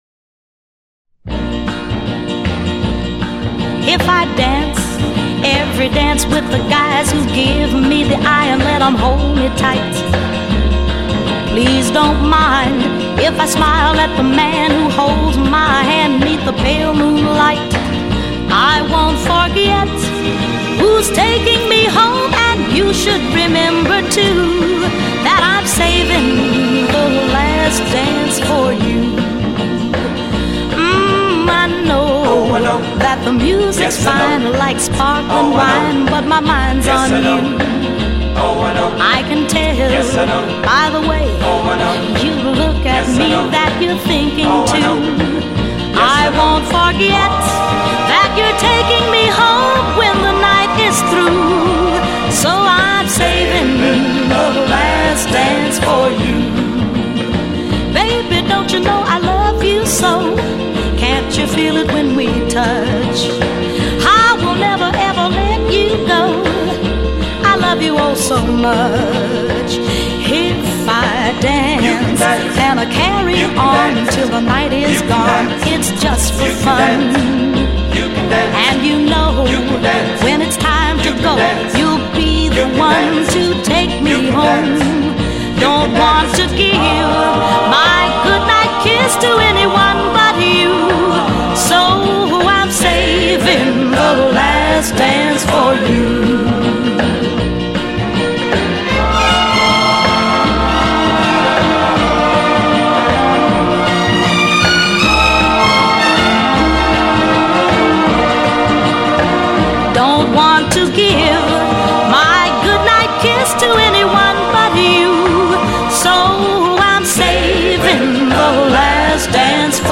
Genre: Pop
Style: Vocal